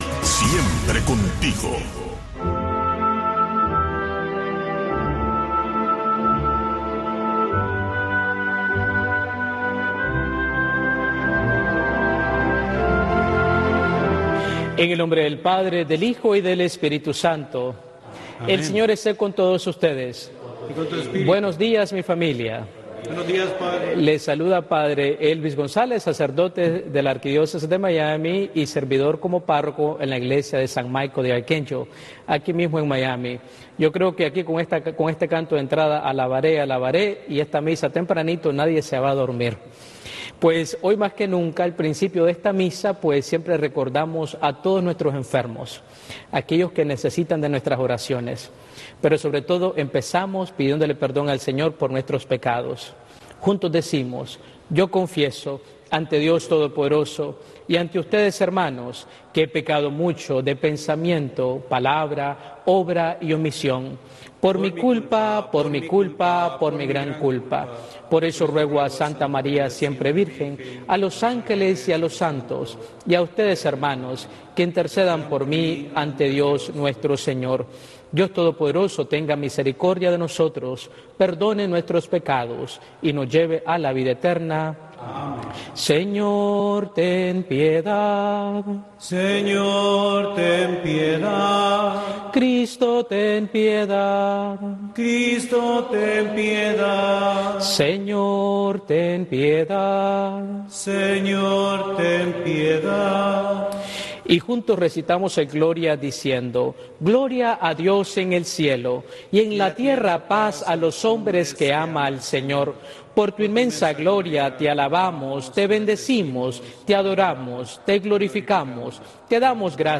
La Santa Misa